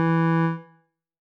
添加三个简单乐器采样包并加载（之后用于替换部分音效）